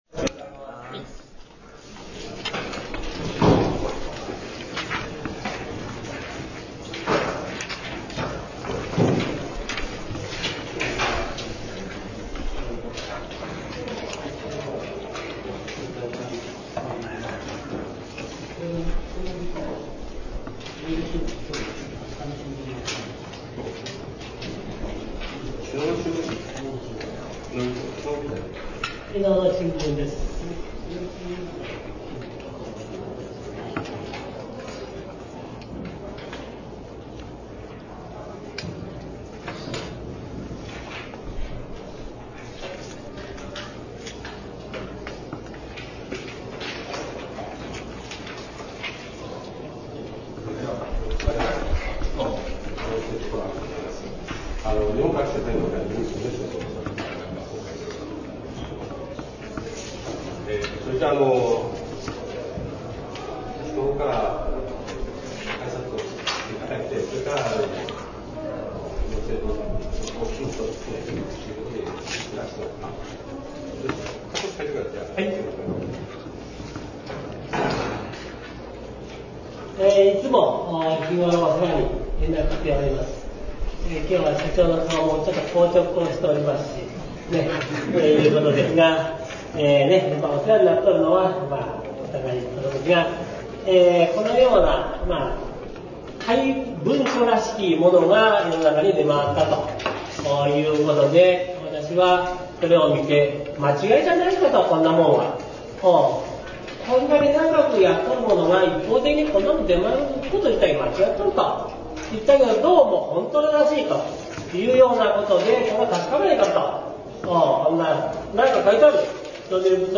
2.場所 :（株）豊明花き会議室